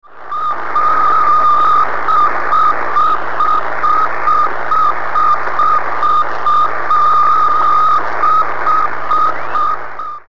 It orbits the Earth every 96.2 minutes at a speed of 18,000 mph, emitting a beeping telemetry beacon signal.